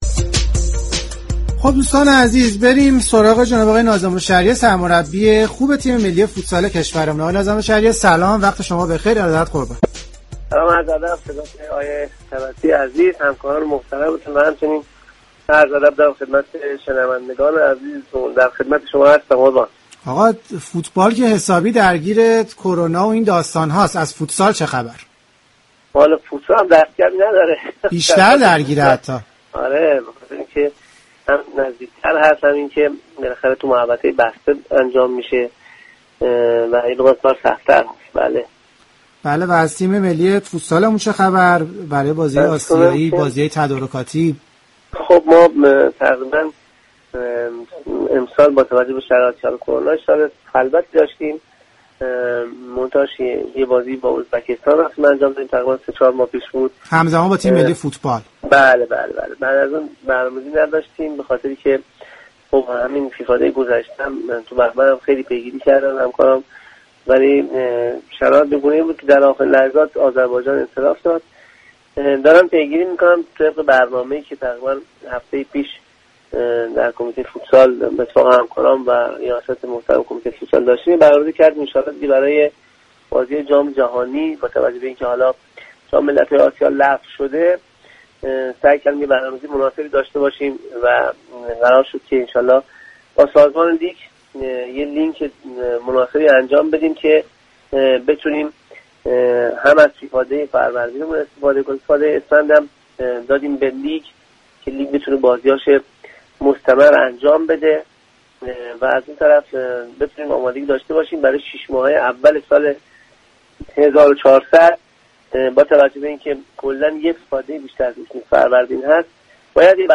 به گزارش رادیو ورزش؛ ناظم الشریعه، سرمربی تیم ملی فوتسال، در خصوص مسابقات مهم تیم ملی و شرایط آماده سازی بازیكنان گفت: امسال با توجه به شرایط كرونا، سال خلوتی داشتیم.